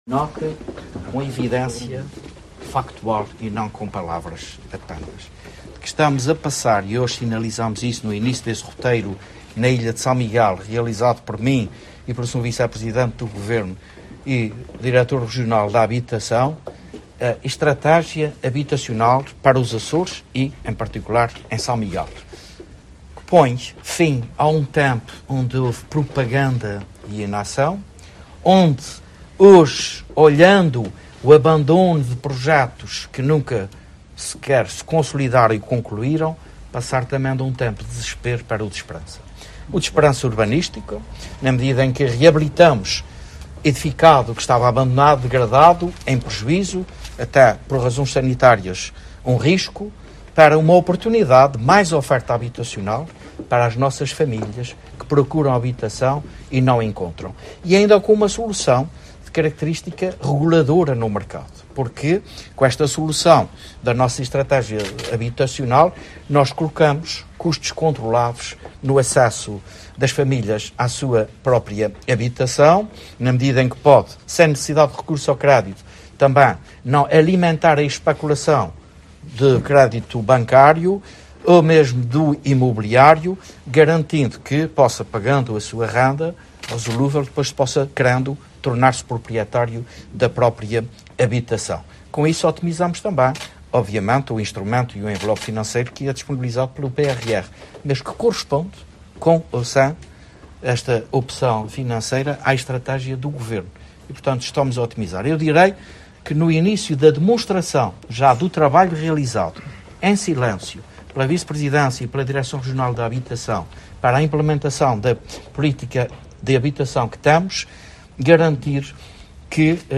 José Manuel Bolieiro falava na Ribeira Grande, na apresentação do projeto para o empreendimento habitacional de Trás-Os-Mosteiros, que marcou o arranque de dois dias de visita a investimento